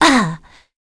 Pavel-Vox_Landing.wav